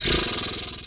pgs/Assets/Audio/Animal_Impersonations/horse_2_breath_01.wav at master
horse_2_breath_01.wav